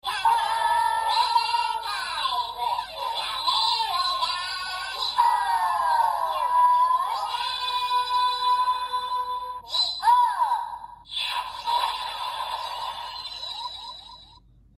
时王饱藏音效.MP3